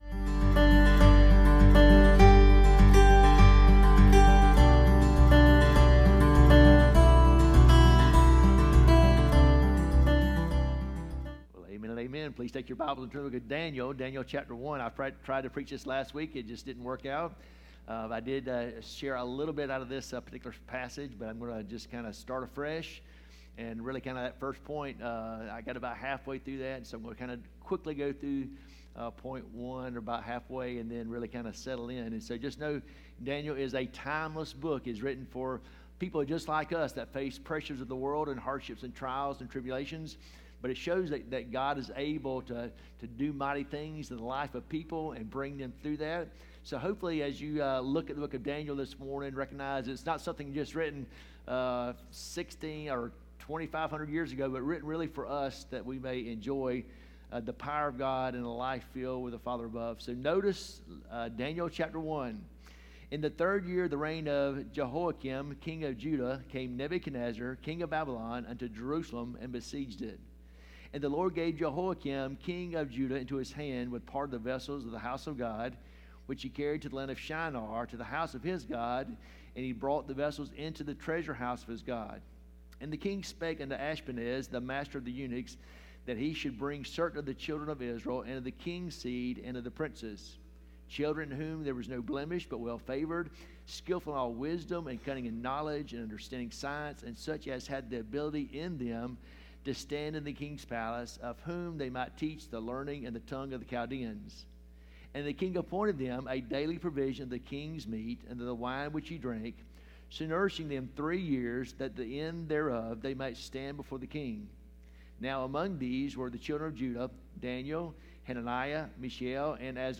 Sermons 2 – Sardis Baptist Church | Worthington Springs, FL